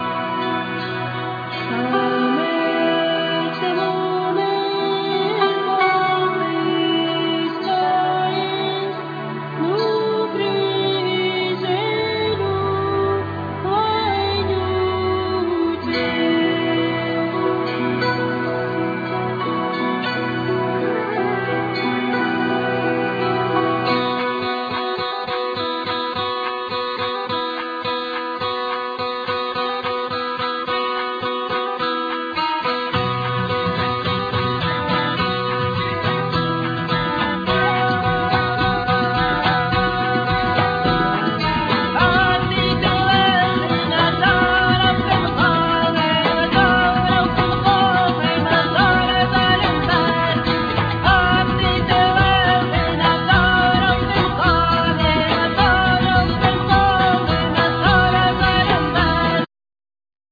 Vocal,Violin
Bagpipe,Accordion,Vocals
Kokle,Keyboards,Kalimba,China flute,Vocals
Acoustic & Electric guitars
Fretless,Acoustic & Double bass